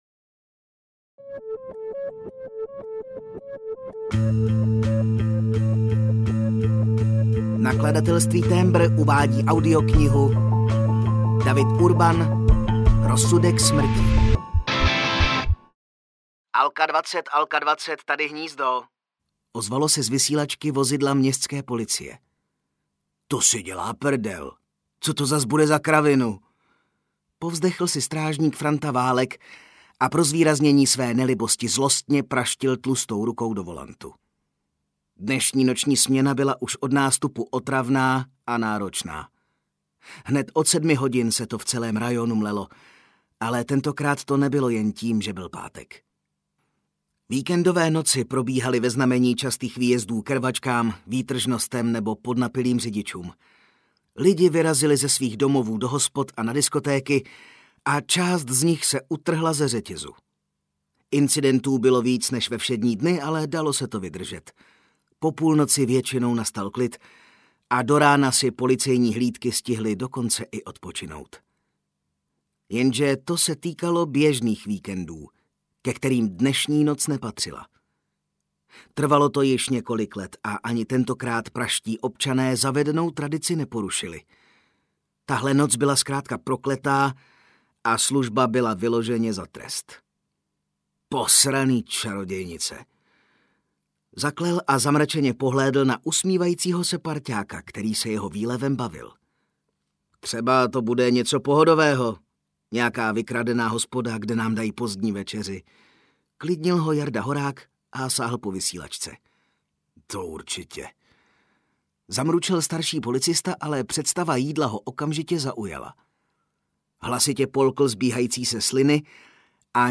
Rozsudek smrti audiokniha
Ukázka z knihy
rozsudek-smrti-audiokniha